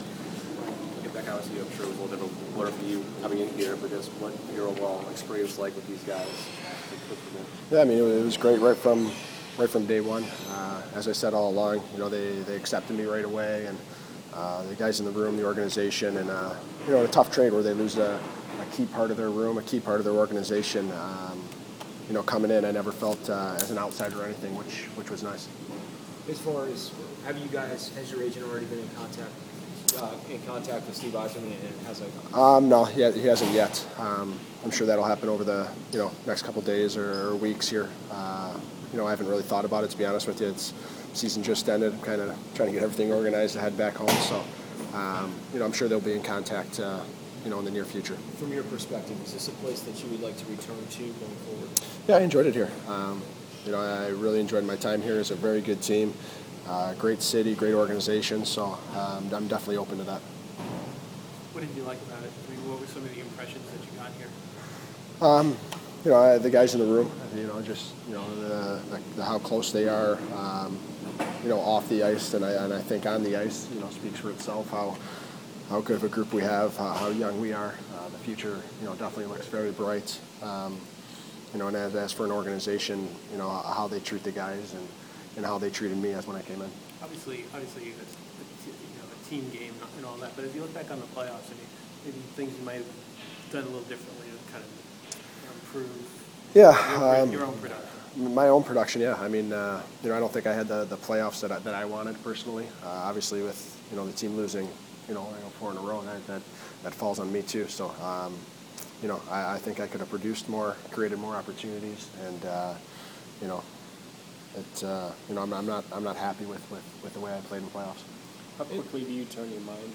Callahan Exit Interview